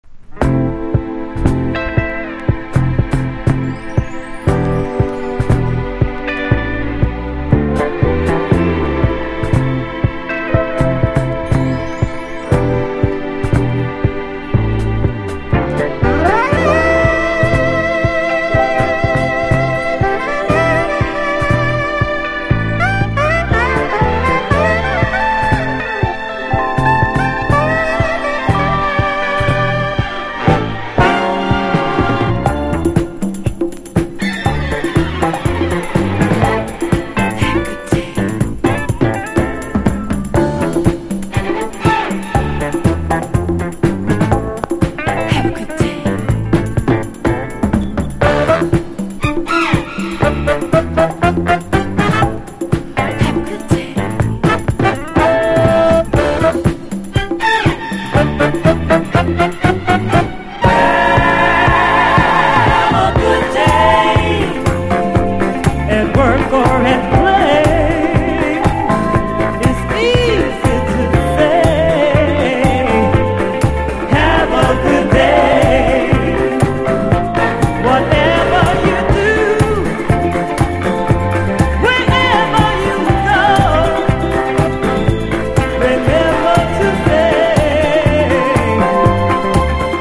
中盤にディスコ・ブレイクが入った軽快なダンス・クラシック！！